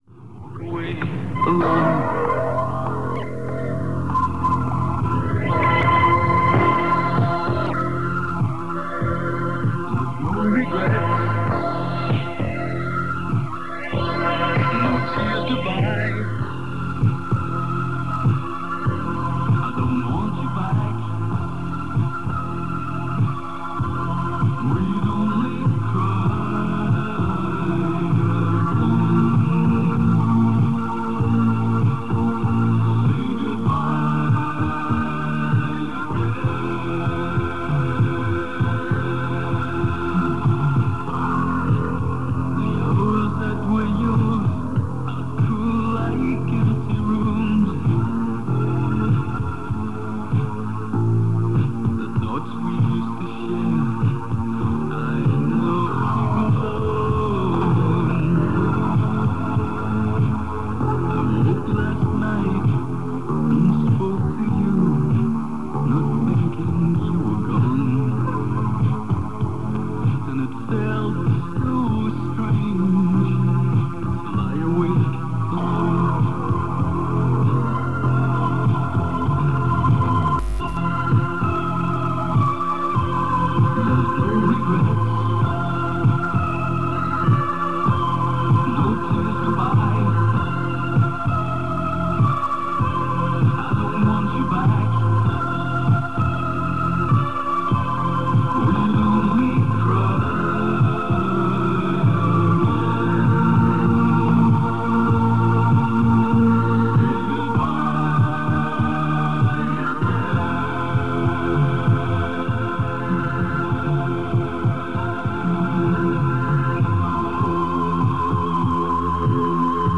Along with the usual mixture of contemporary hits and oldies, there’s mention of the ongoing World Cup tournament and the Sunshine Radio rock concert in Malahide Castle that evening. Commercial breaks include an advert for Tamango’s nightclub in the Sands Hotel in Portmarnock where Sunshine was based. There’s also a cash prize giveaway based on a car registration number.
After a wobbly start, audio quality is good.